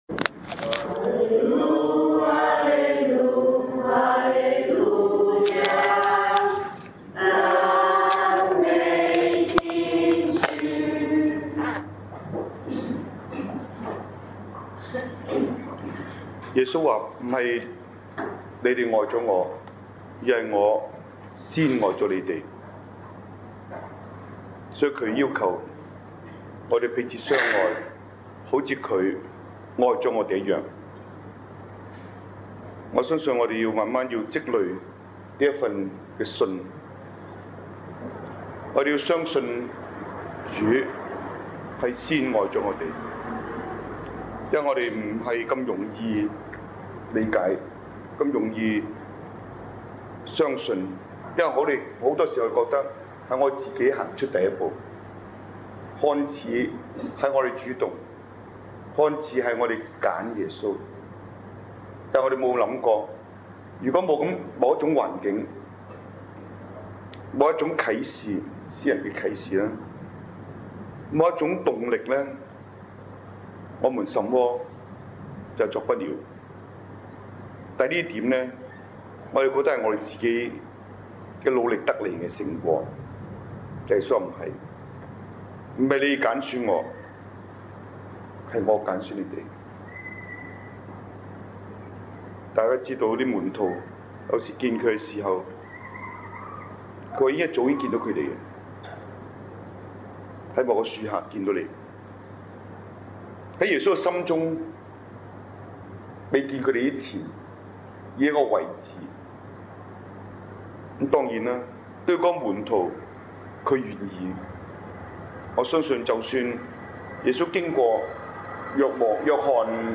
早上, 在嘉諾撒小學講道, 耶穌聖心彌撒